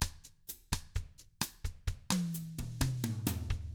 129BOSSAF2-L.wav